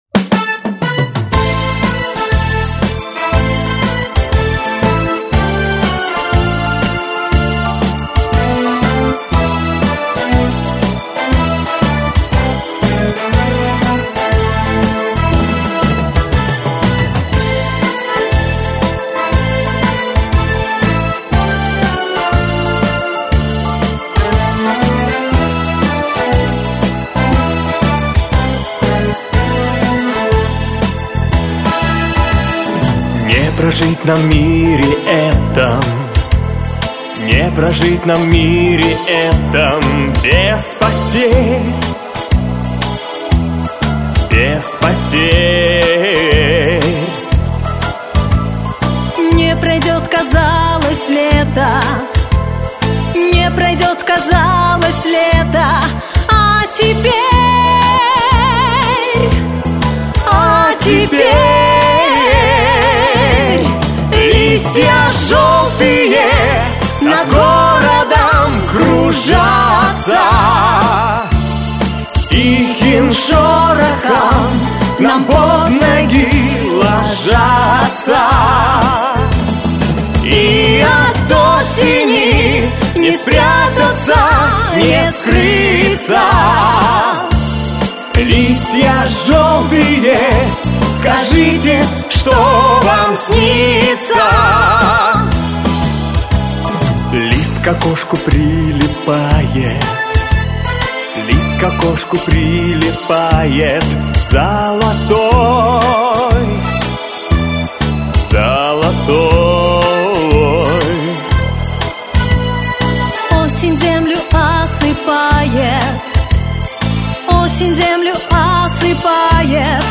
Кроме того, неизвестен участник дуэта, мужская партия.
А почему сомнения - по-моему, легкий прибалтийский акцент.